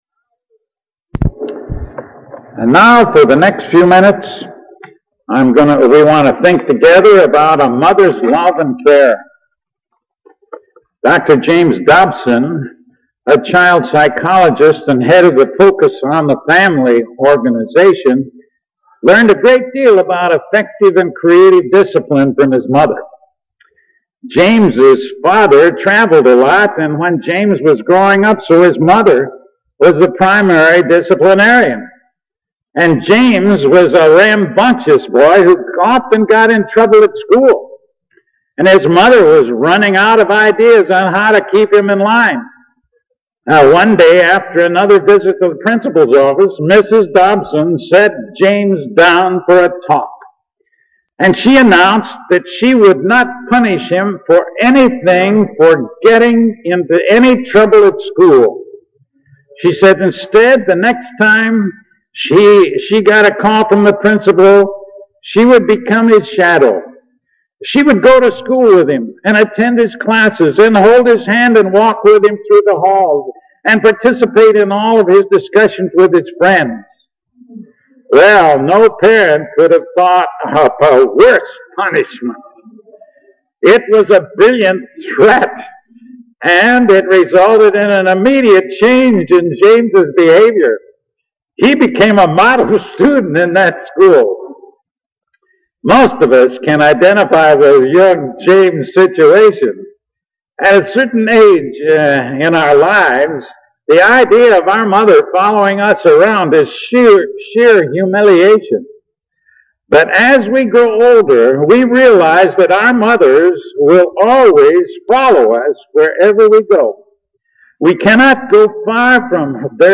Mother’s Day Message Mothers Love and Prayer Filed Under: All Christian Sermons , Christian Men and Women , Love